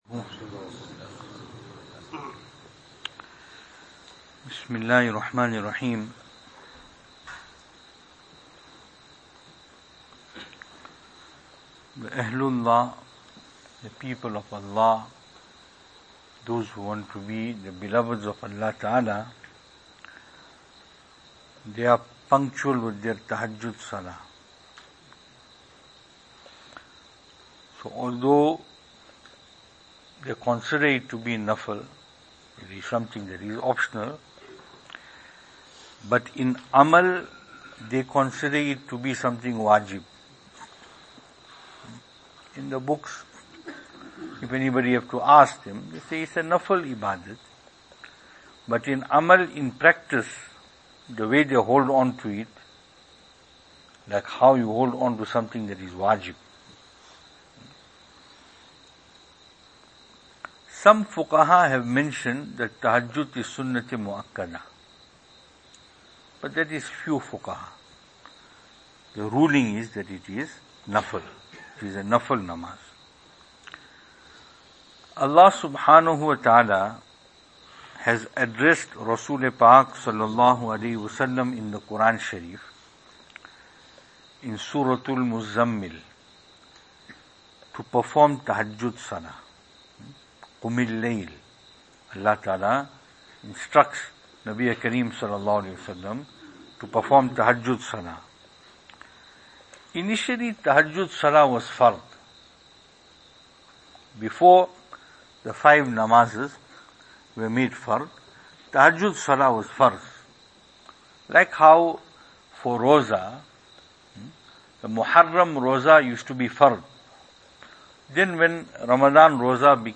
Venue: Albert Falls , Madressa Isha'atul Haq Service Type: Zikr